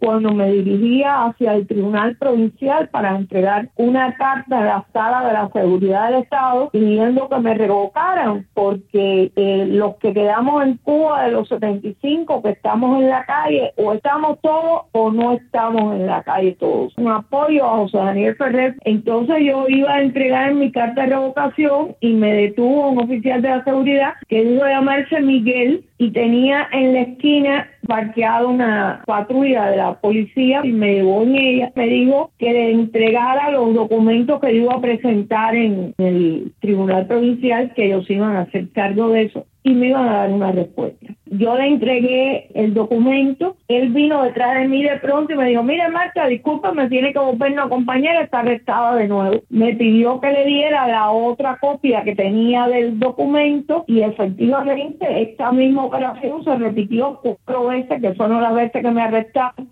Declaraciones de Martha Beatriz Roque